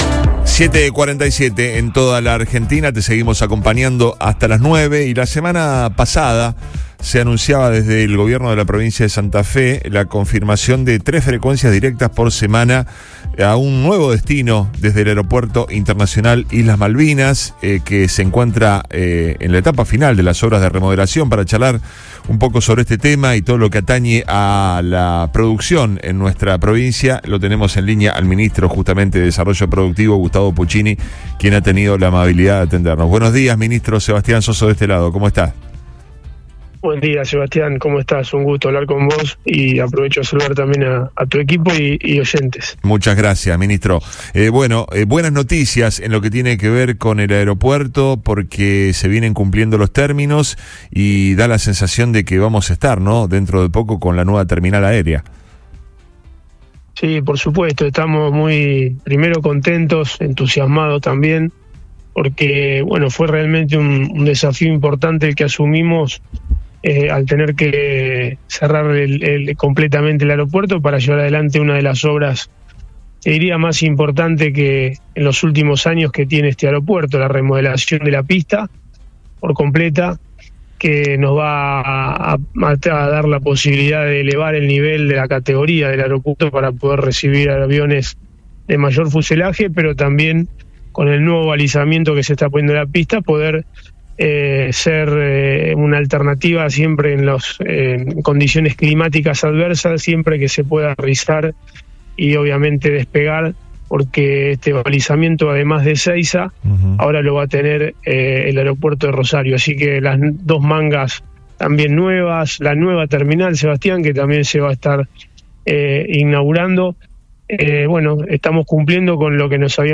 En diálogo con el programa Río Extra Primera Mañana de Río 96.9, el funcionario destacó que la remodelación de la pista —realizada en un tiempo récord de tres meses— posicionará a la terminal rosarina en un nivel de élite tecnológica, igualando las capacidades de seguridad de Ezeiza.